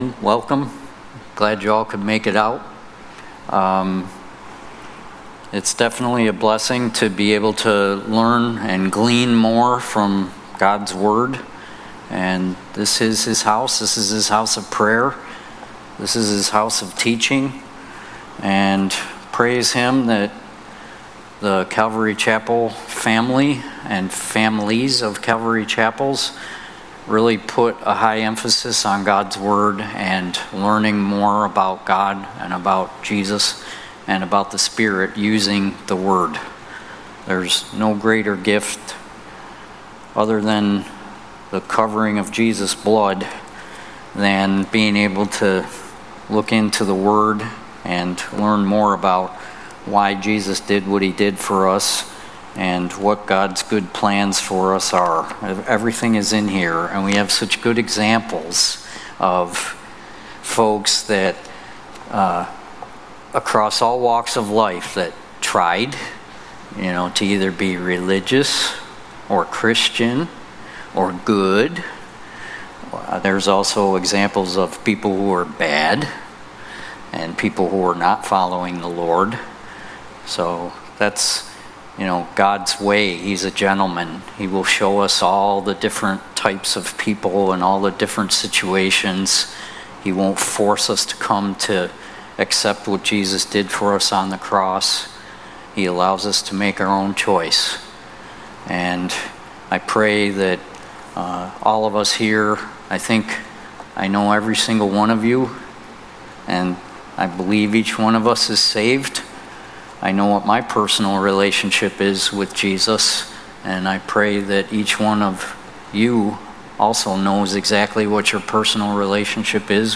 Genesis 30 – Sunday Night Bible Study